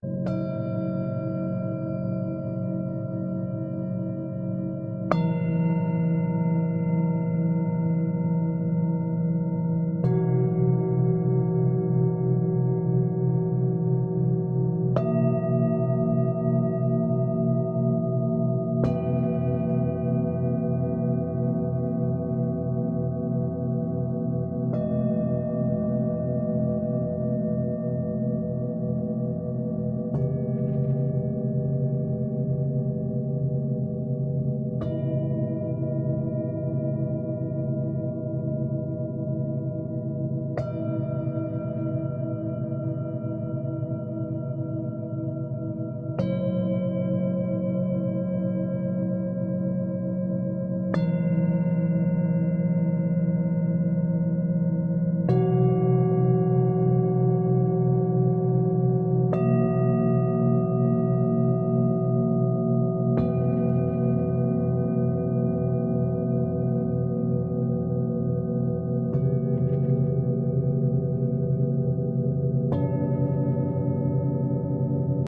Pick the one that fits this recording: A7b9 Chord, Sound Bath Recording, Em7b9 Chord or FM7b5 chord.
Sound Bath Recording